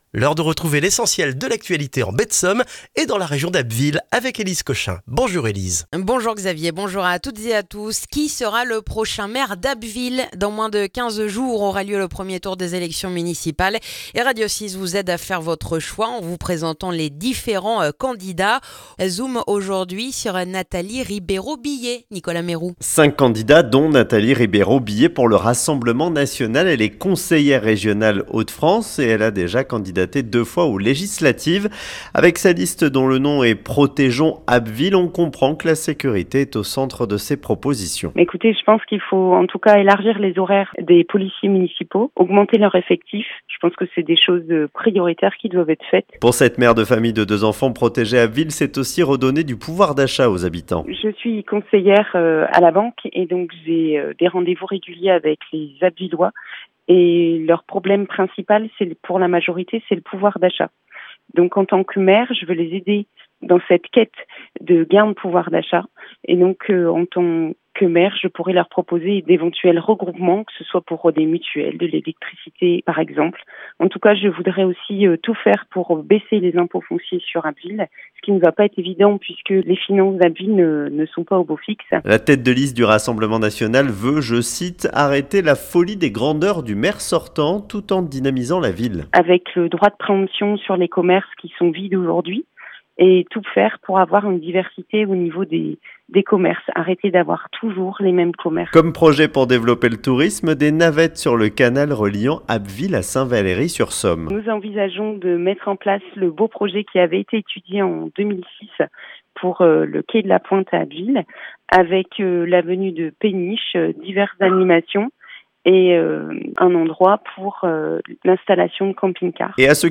Le journal du lundi 2 mars en Baie de Somme et dans la région d'Abbeville